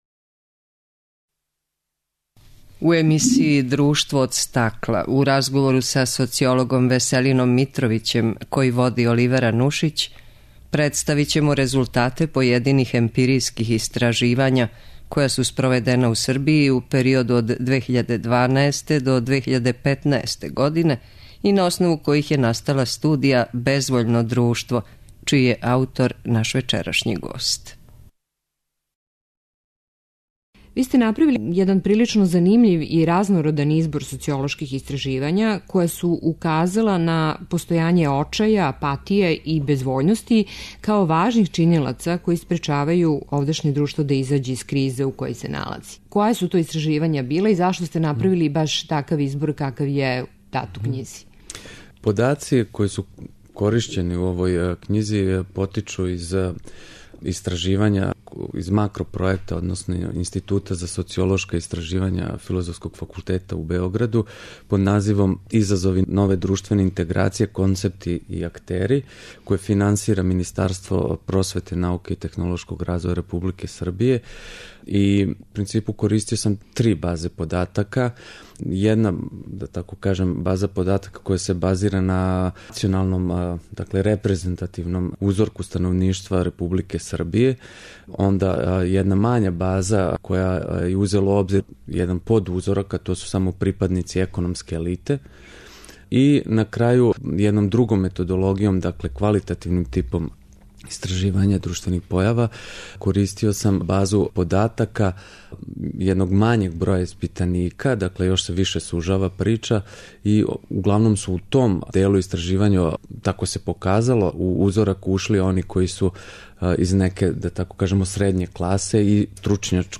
у емисији ДРУШТВО ОД СТАКЛА у разговору